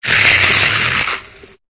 Cri de Solochi dans Pokémon Épée et Bouclier.